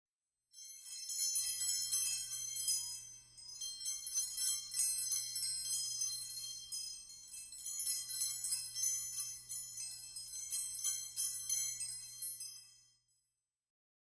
Woodstock Chimes, Bells and Gongs